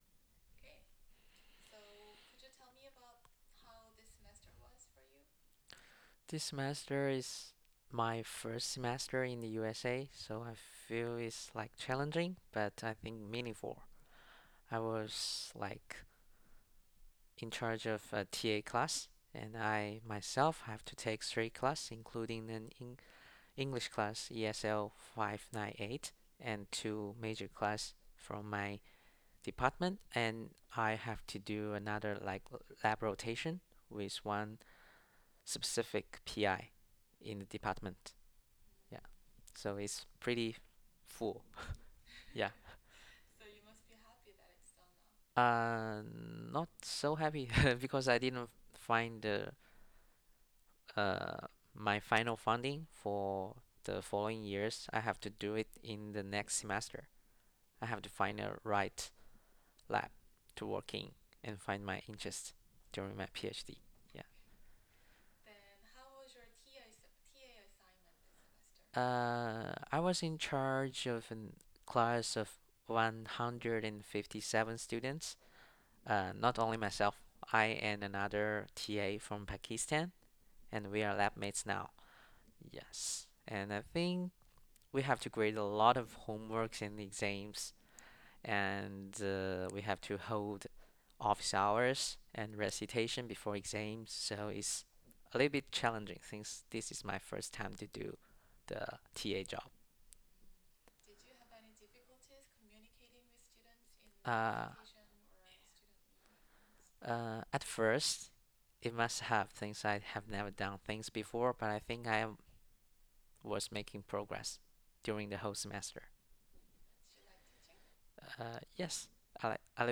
This task aimed to elicit spontaneous speech from non-native speakers of English. ITAs were asked to answer questions related to their everyday life, teaching, and research work in a semi-structured interview format. Each session lasted for at least five minutes and included speech by the interviewer, who was a graduate research assistant.
Participant 12 Spontaneous speech Baseline 1